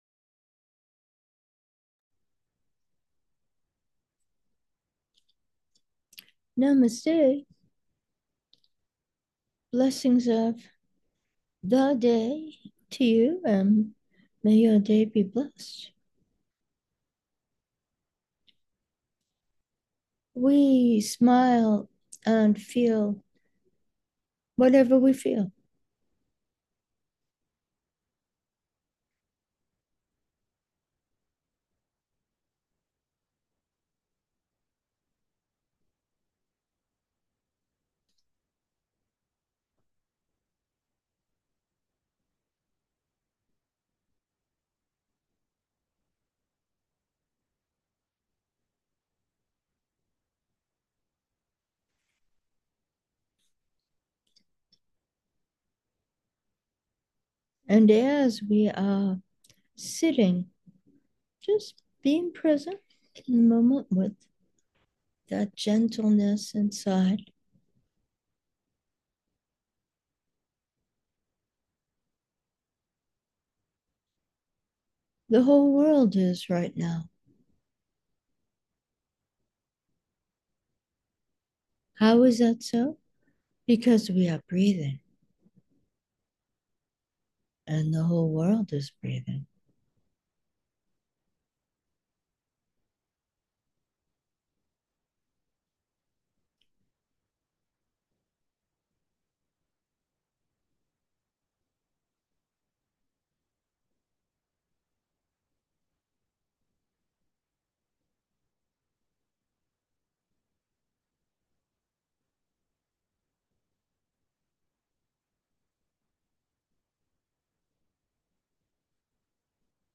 Meditation: metta, sensing